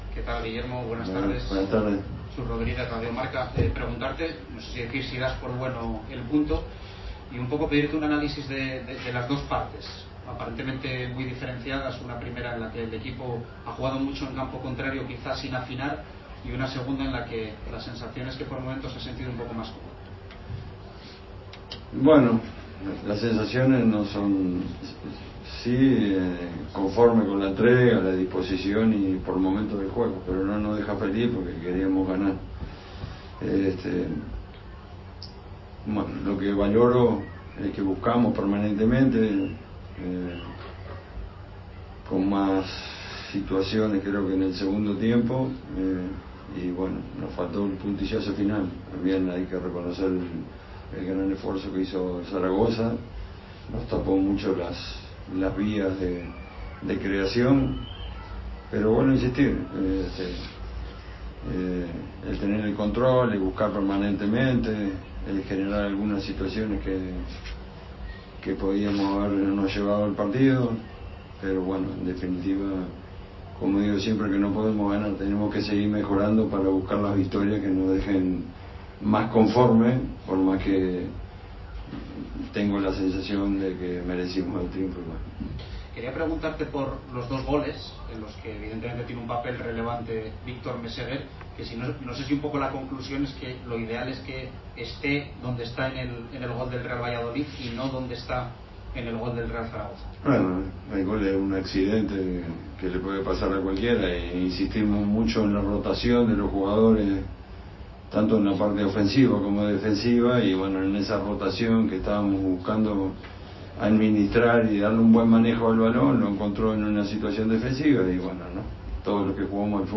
aquí la rueda de prensa completa